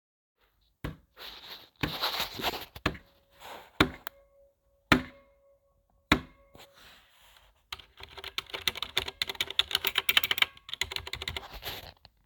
Das ist auch nicht wirklich ein kurzes Pingen als vielmehr ein metallenes Hallen und das bei jedem Tastenanschlag.
Habe mal versucht, den Ton mit der Diktiergeräte-Funktion meines Smartphones einzufangen...